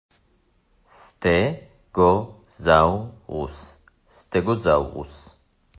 סט-גו–זאו-רוס